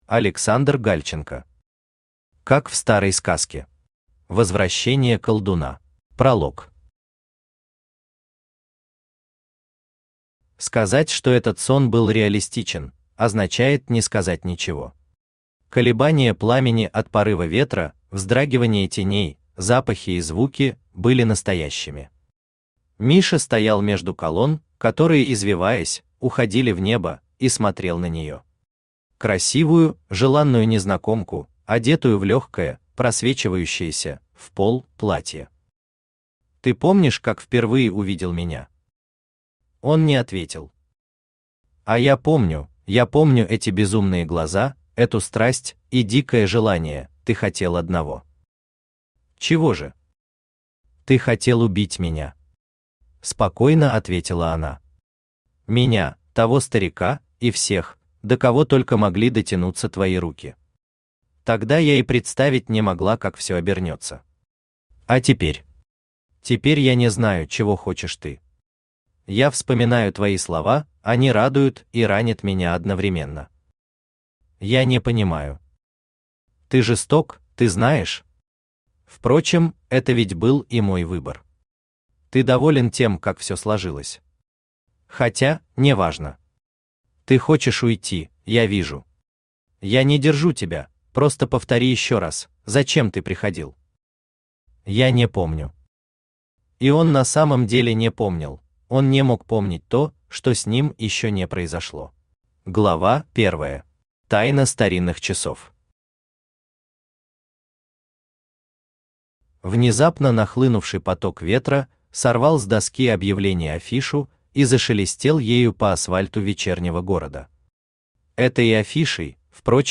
Аудиокнига Как в старой сказке. Возвращение колдуна | Библиотека аудиокниг
Aудиокнига Как в старой сказке. Возвращение колдуна Автор Александр Николаевич Гальченко Читает аудиокнигу Авточтец ЛитРес.